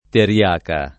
triaca [ tri- # ka ]